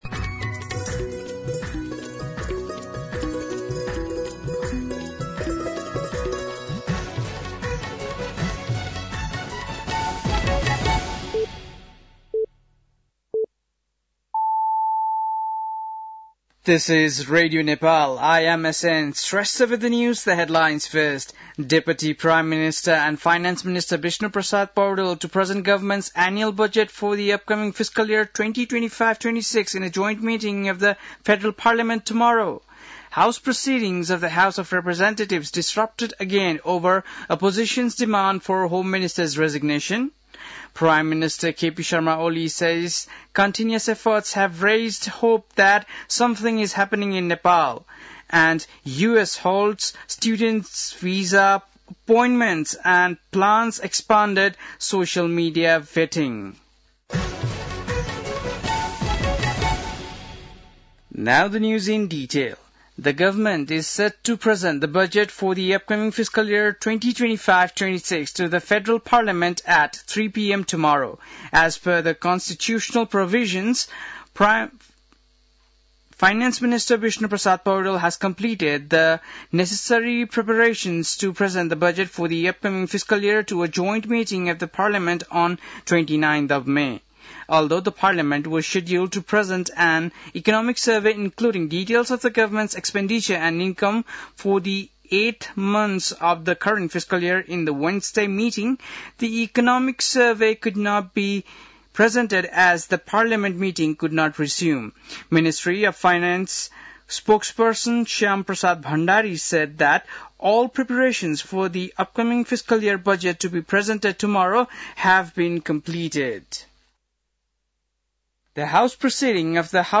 बेलुकी ८ बजेको अङ्ग्रेजी समाचार : १४ जेठ , २०८२
8-pm-english-news-2-14.mp3